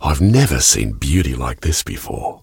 B_beauty_waterfall.ogg